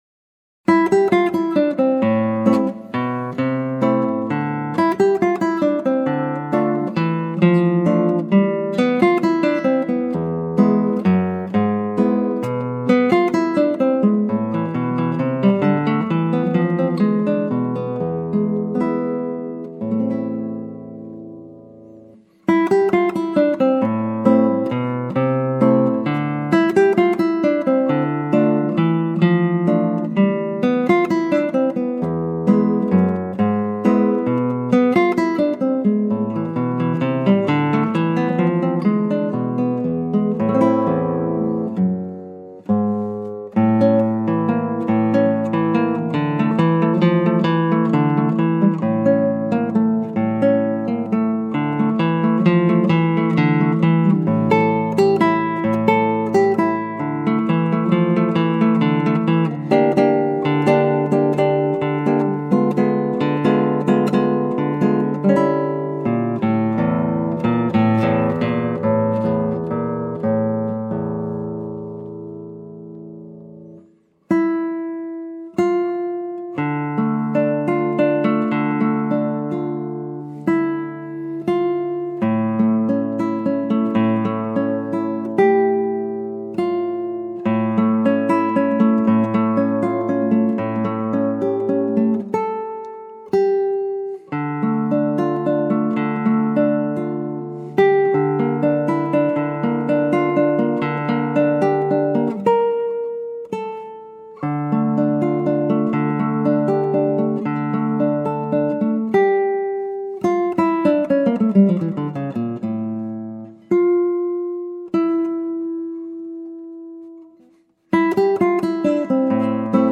Tangos & Milongas auf mittlerem Level
Spanische Gitarrenmusik ; 3